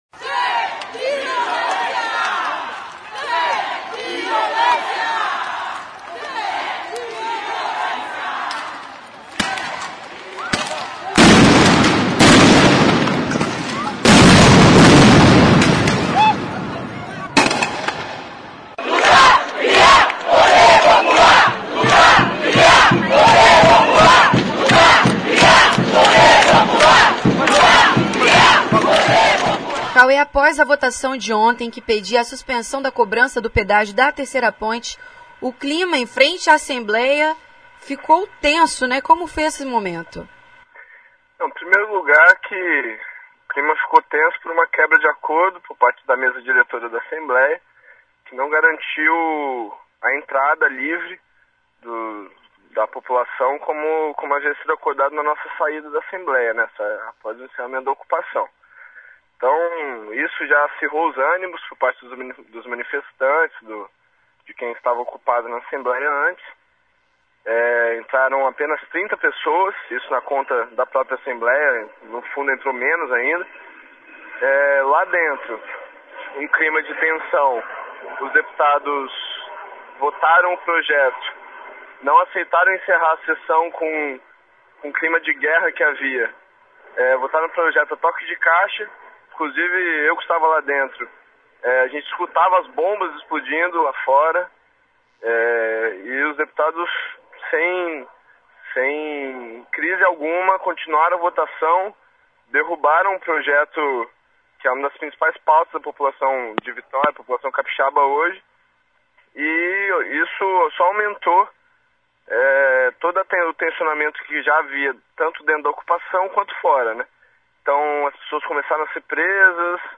Confira a entrevista com o estudante e manifestante